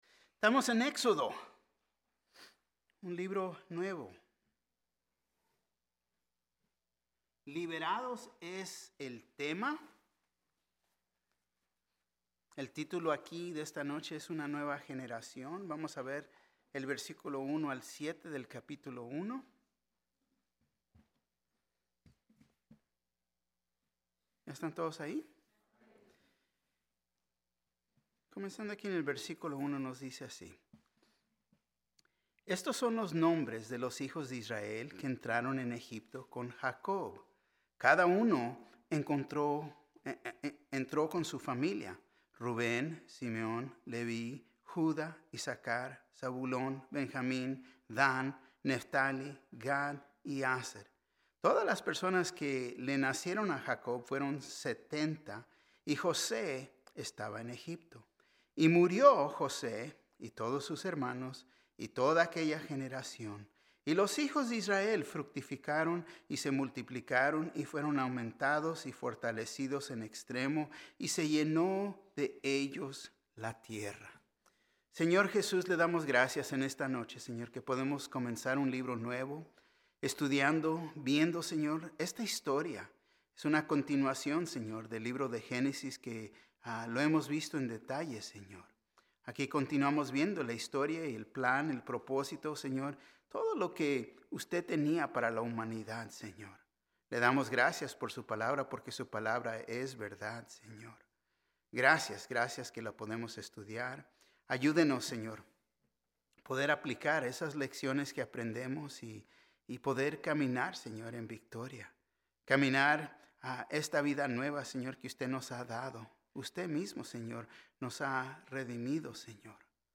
Mensaje: ““Caminemos en Unidad” Parte I